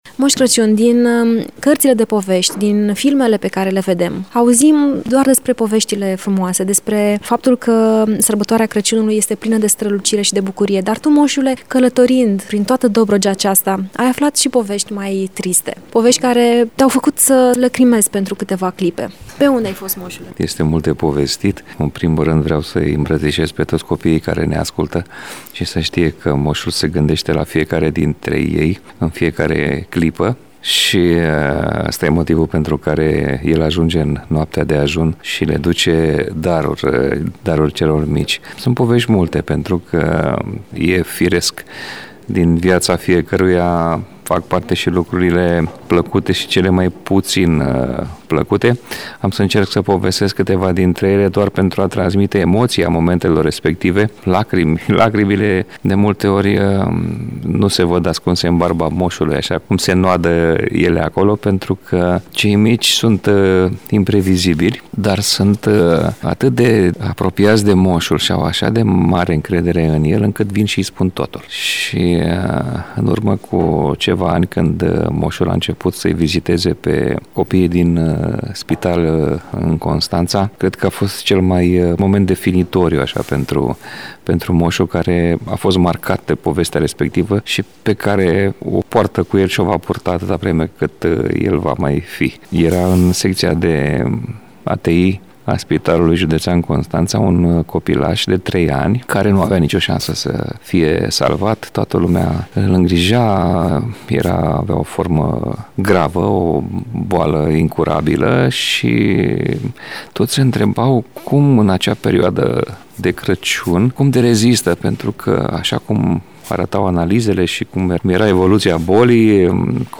în interviul următor.